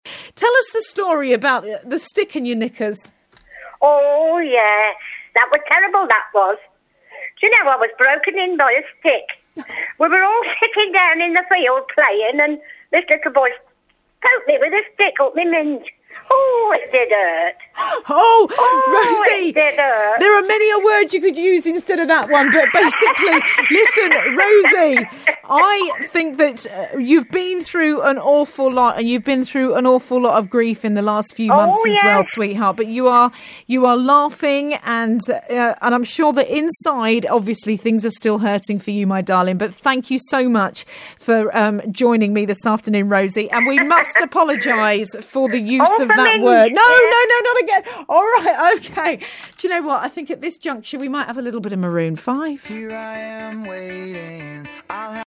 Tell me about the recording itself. The special relationship she has with callers means that, well, sometimes they say the unthinkable. As heard here on BBC WM.